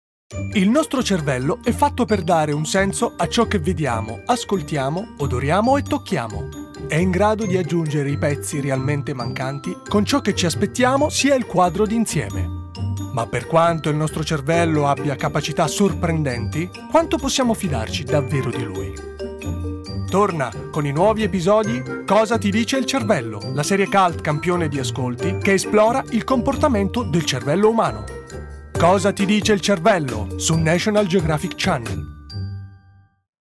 Speaker radio-televisivo. Voce calda e graffiata
Sprechprobe: Werbung (Muttersprache):